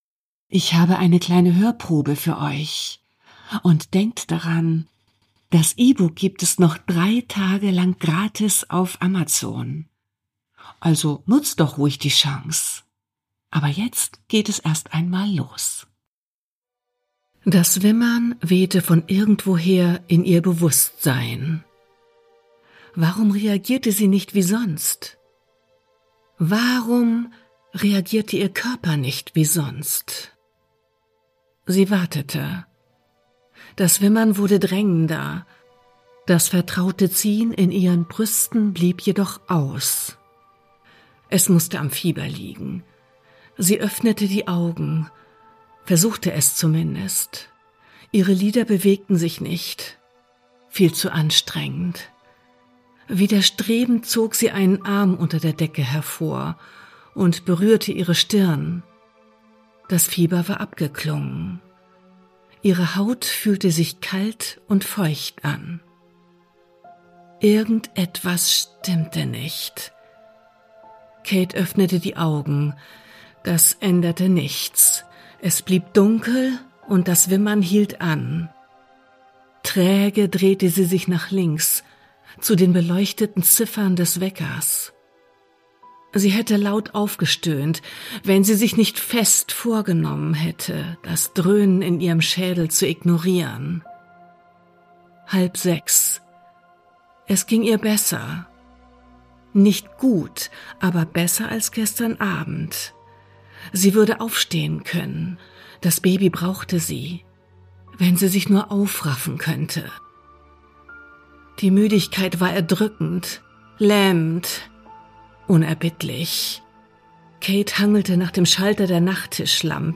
Bonus Hörprobe: "Das ist nicht mein Kind"